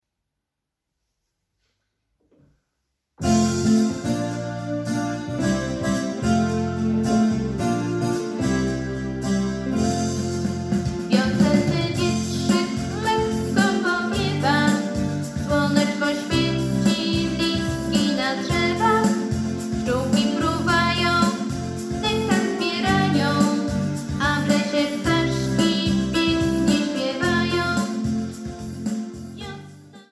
Posłuchaj próbki wersji ze śpiewem